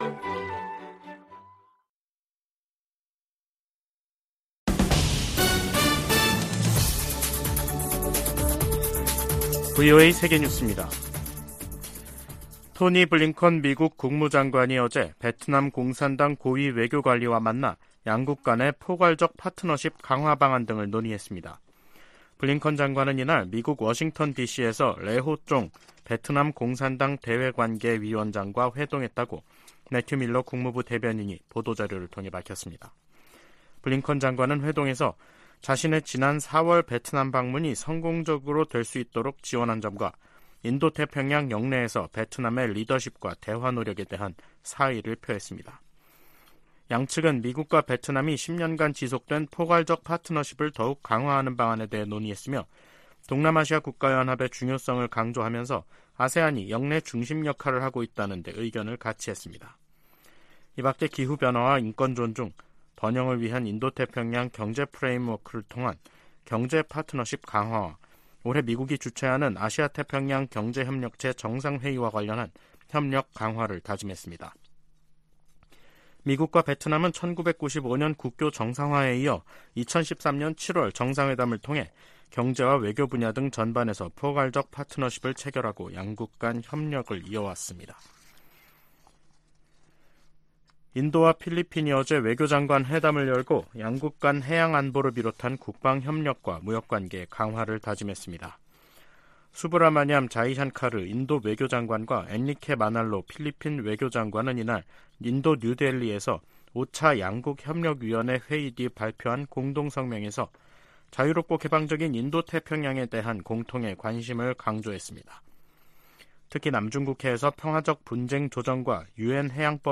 VOA 한국어 간판 뉴스 프로그램 '뉴스 투데이', 2023년 6월 30일 2부 방송입니다. 김영호 한국 통일부 장관 후보자는 통일부 역할이 변해야 한다며, 북한 인권을 보편적 관점에서 접근해야 한다고 말했습니다. 유엔 화상회의에서 미국은 북한에 모든 납북자들을 송환해야 한다고 촉구했습니다. 핵 탑재 가능한 미국 오하이오급 잠수함이 머지않아 한국에 기항할 것이라고 미 국방부 대변인이 밝혔습니다.